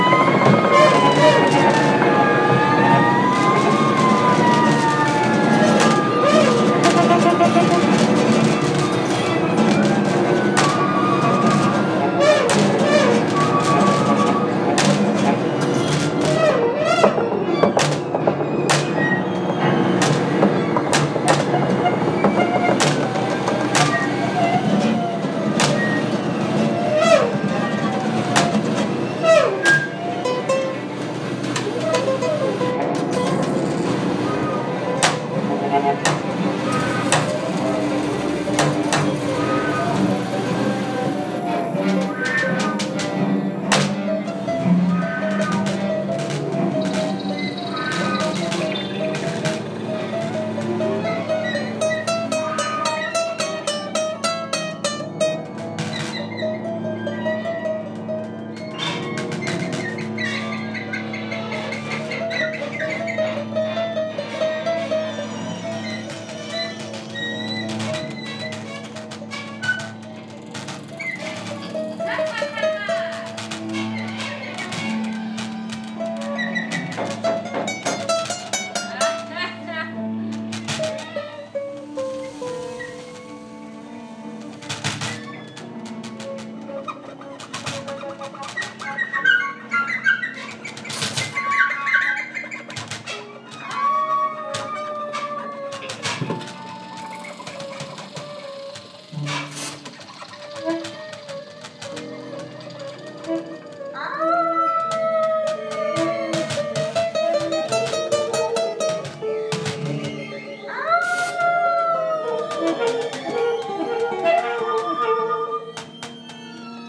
im Foyer die Klänge der Nacht herauf.
Sie schnarcht und kracht
Sie jault und bellt
Sie swingt und jazzt